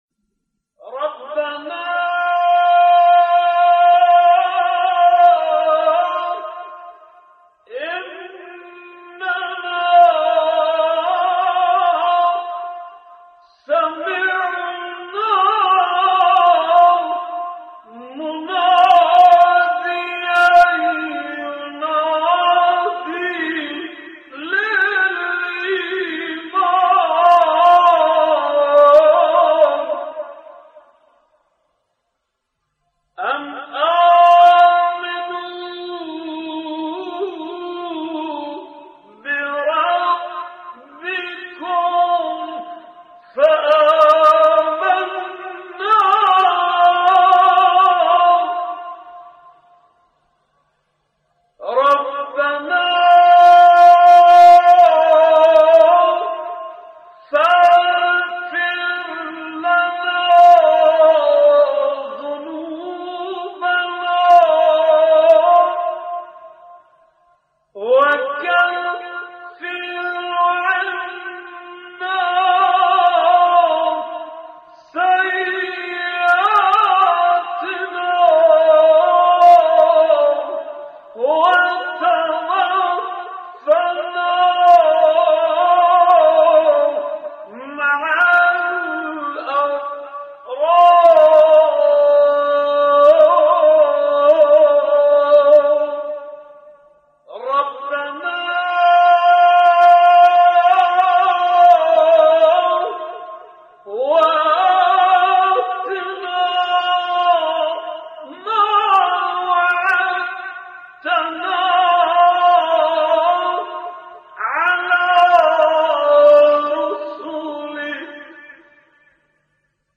نوای زیبای ربنا با صدای مرحوم سید جواد ذبیحی